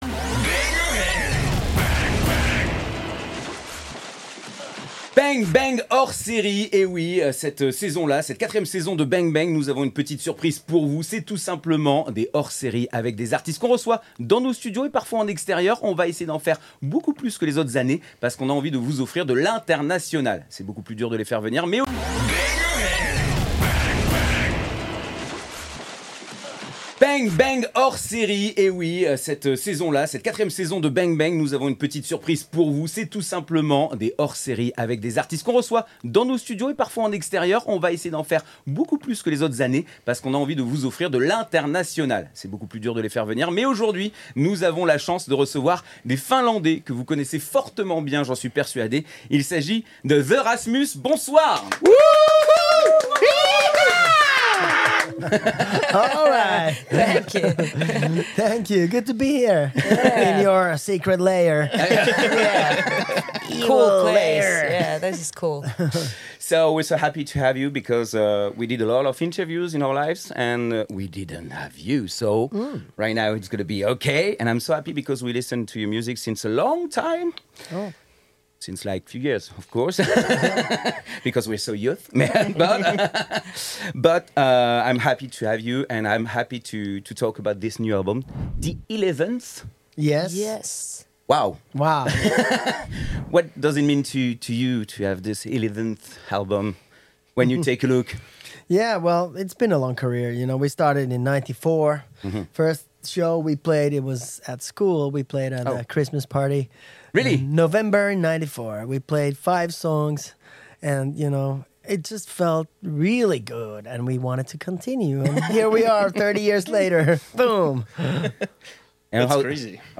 BANG! BANG! Interview : THE RASMUS - RSTLSS